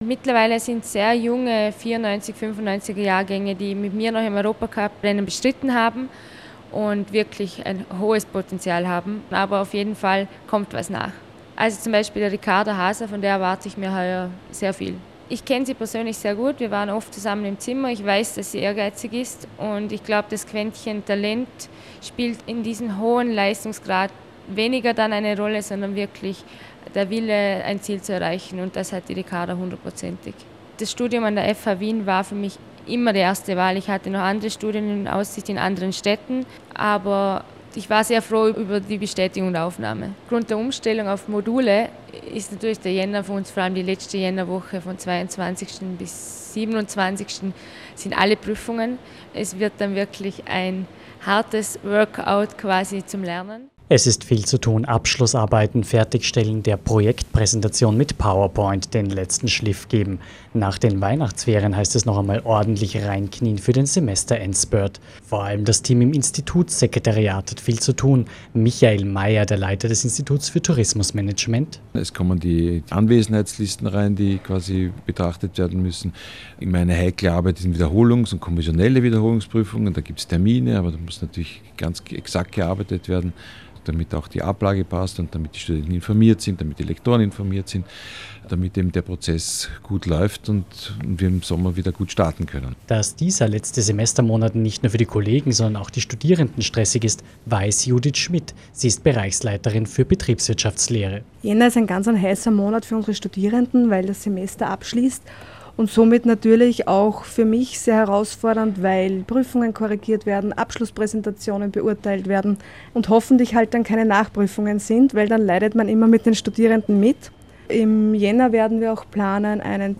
Beitrag Semester-Endspurt am Institut für Tourismusmanagement